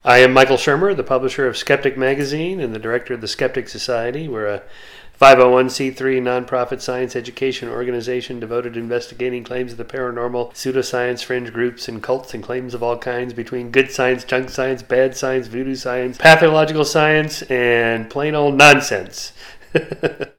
Dr. Shermer introduces himself: